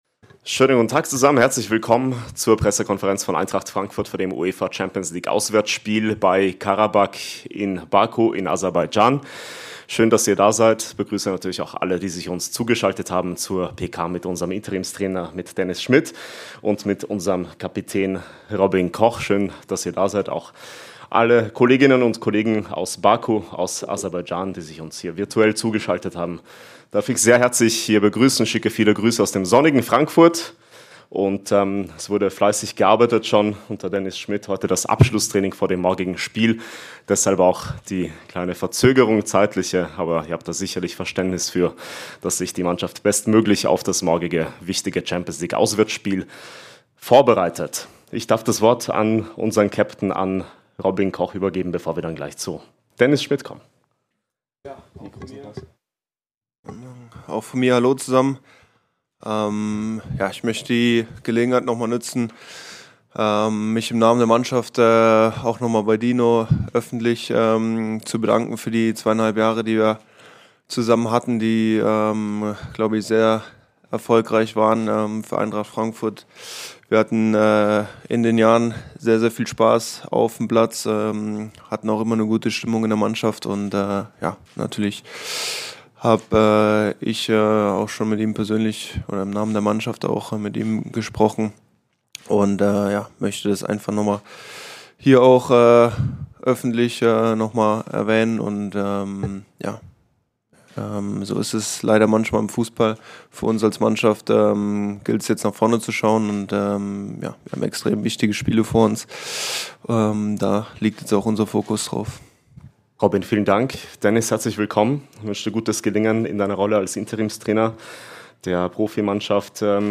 Die Pressekonferenz vor dem siebten Spieltag der UEFA Champions League